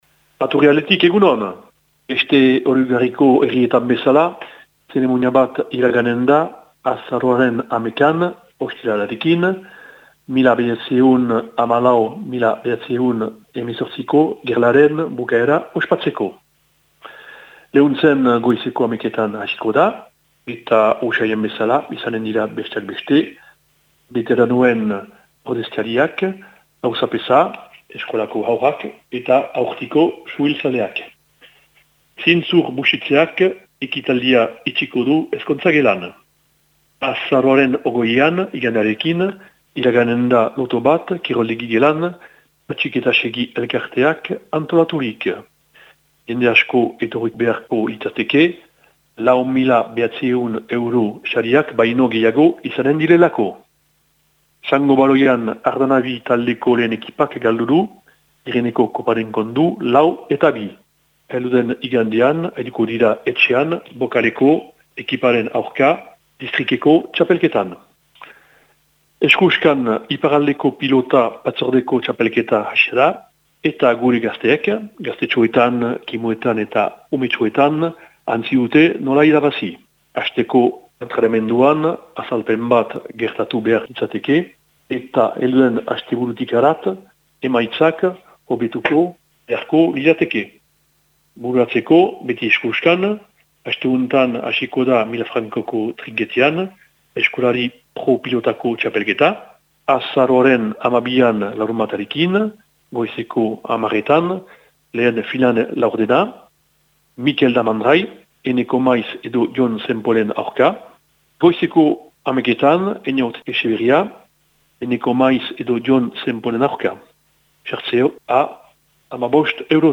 Azaroaren 10eko Lehuntzeko berriak